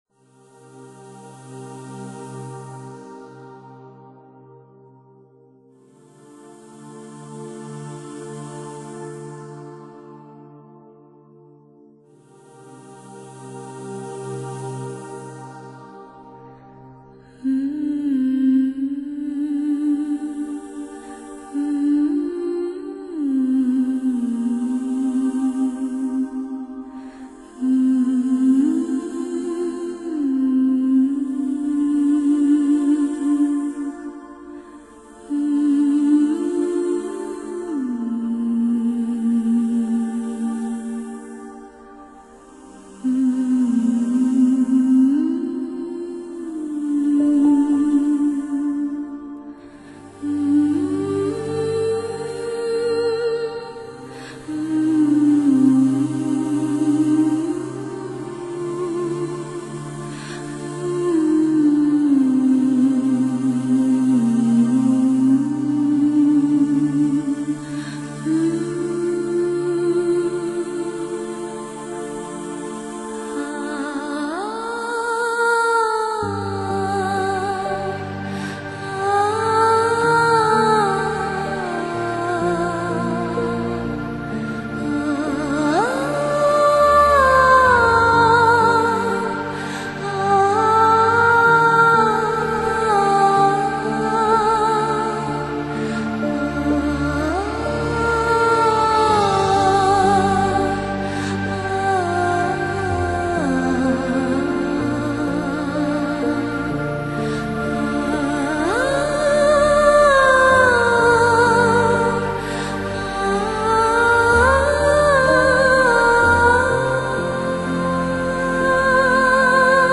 [1/9/2009]一首动听的吟唱，但不知名字